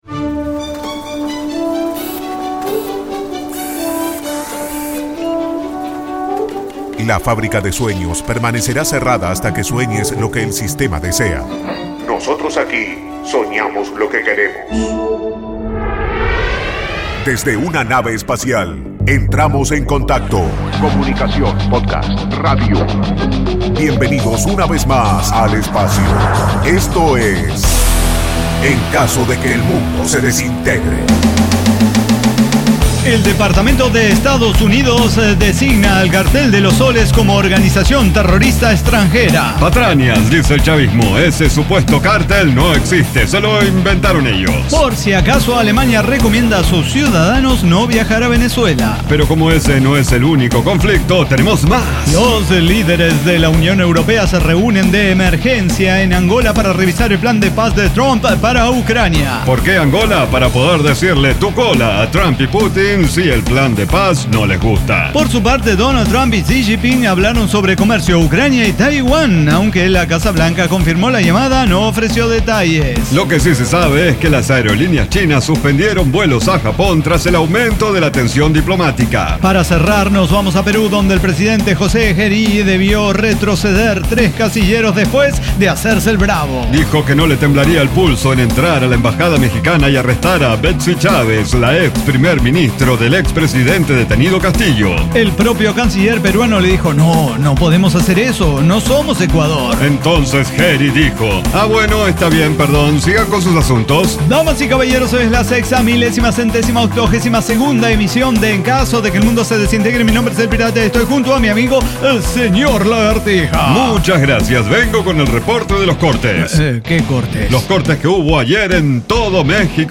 Diseño, guionado, música, edición y voces son de nuestra completa intervención humana.